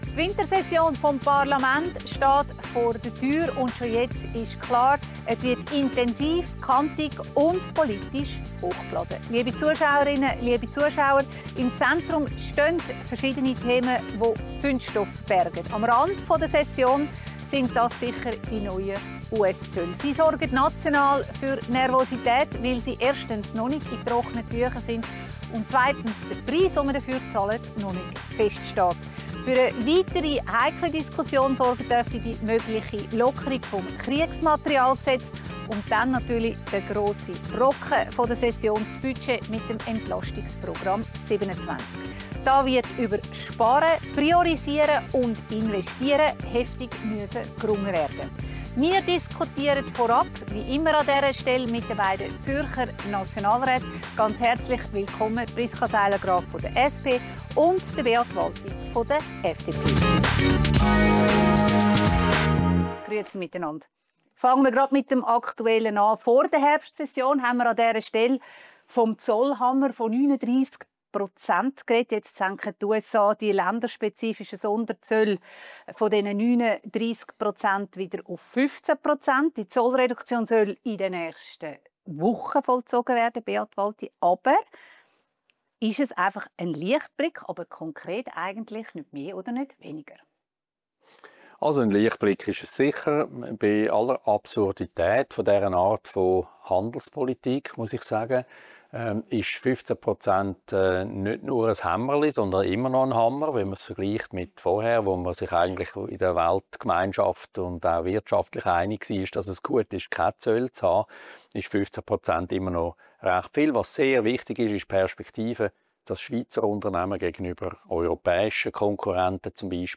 diskutiert mit den beiden Zürcher Nationalräten Priska Seiler Graf, SP und Beat Walti, FDP